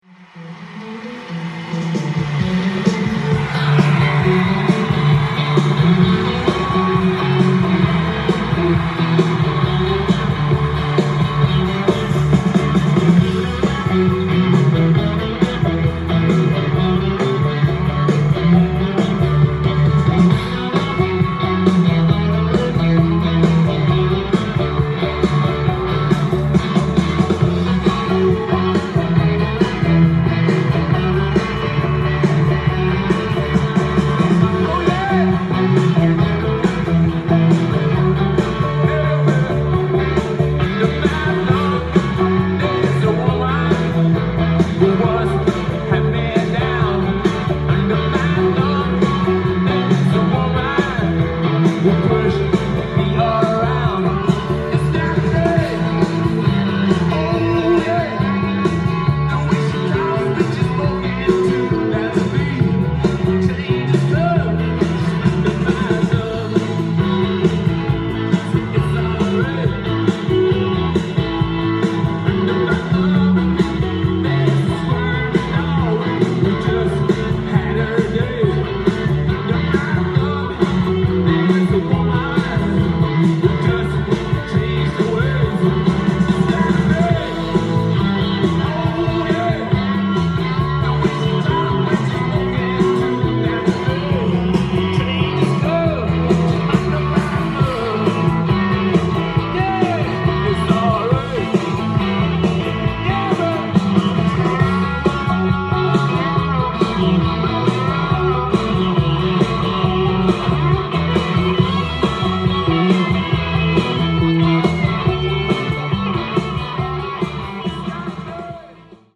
店頭で録音した音源の為、多少の外部音や音質の悪さはございますが、サンプルとしてご視聴ください。
1981年の北米ツアーの模様を収録した’82年発表のライヴ・アルバム。